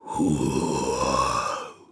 Phillop-Vox_Casting1_jp.wav